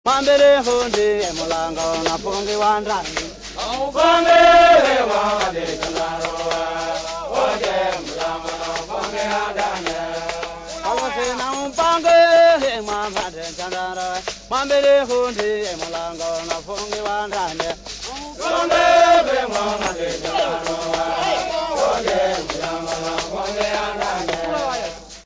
Msego dance song for men and women
Kayamba raft rattles
Indigenous folk music
Mombasa
Kenya
Original format: 15ips reel
Hugh Tracey (Recorded by)